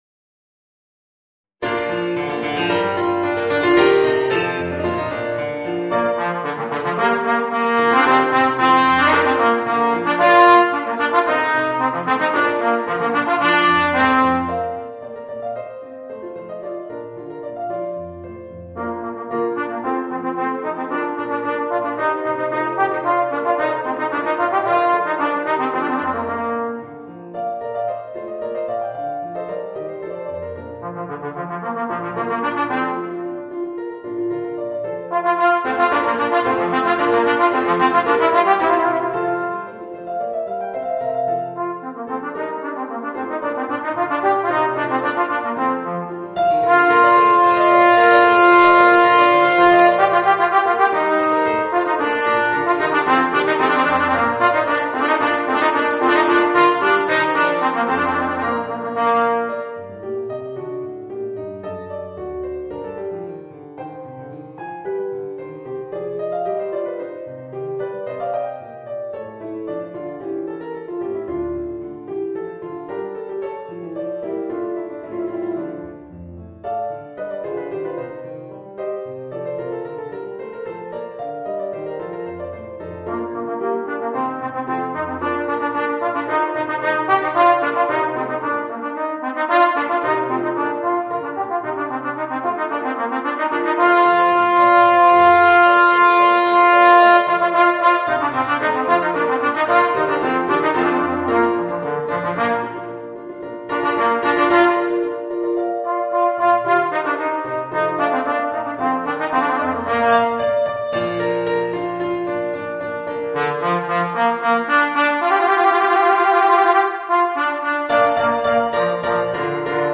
（トロンボーン+ピアノ）